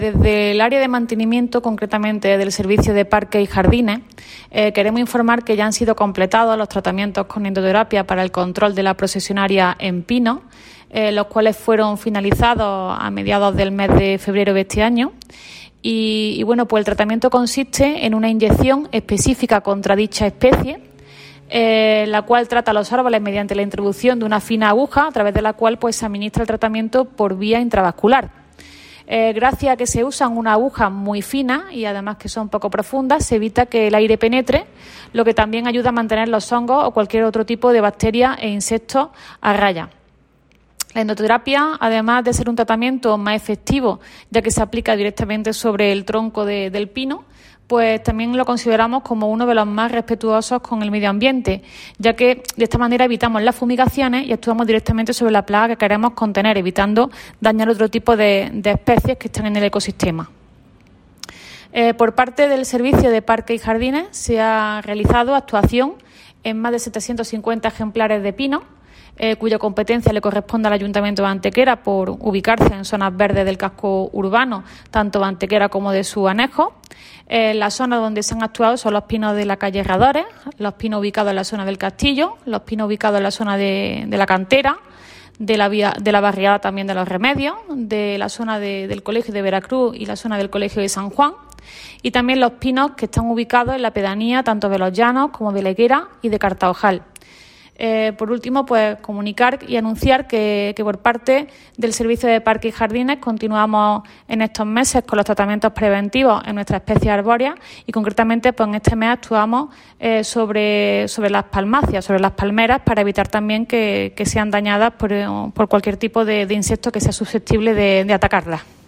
La teniente de alcalde delegada de Mantenimiento del Ayuntamiento de Antequera, Teresa Molina, confirma la conclusión de la campaña de control de plagas de la procesionaria en los más de 750 ejemplares de pinos existentes en espacios verdes municipales de nuestro municipio.
Cortes de voz